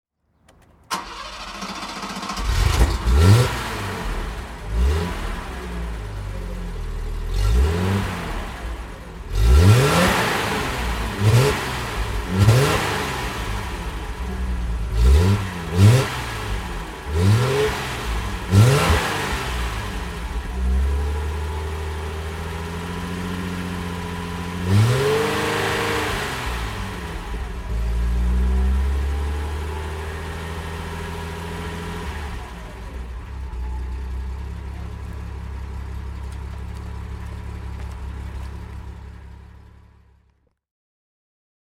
Ford Granada (1980) - Starten und Leerlauf